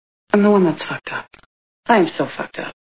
Six Feet Under TV Show Sound Bites